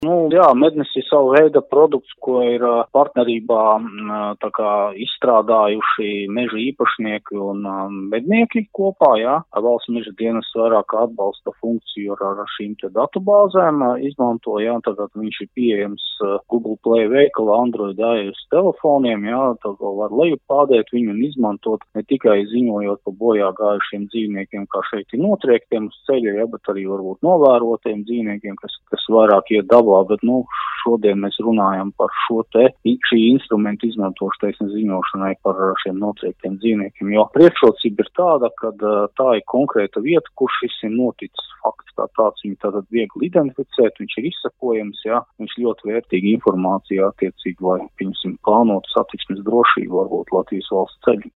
RADIO SKONTO Ziņās par jauno lietotni “Mednis”